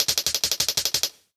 Index of /musicradar/ultimate-hihat-samples/175bpm
UHH_ElectroHatD_175-03.wav